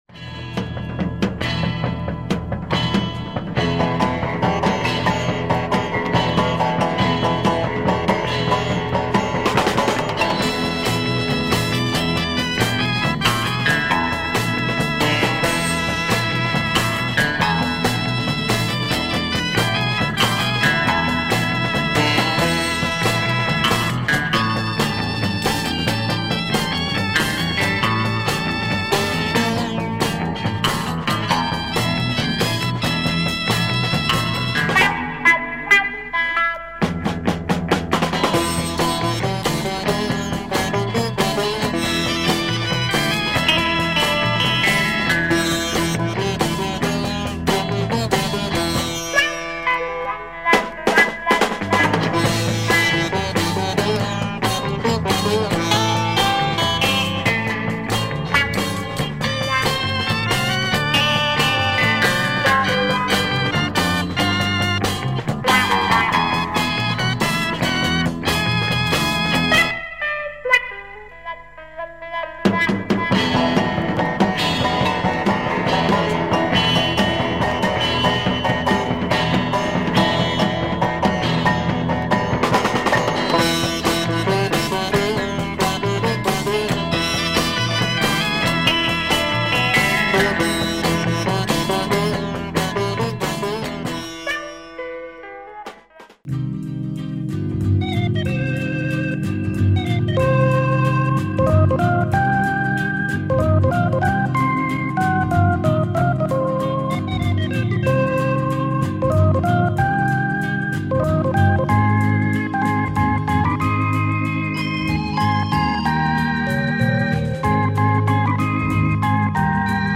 Nice collection of Italian groovy beat and organ pop.
sitar tune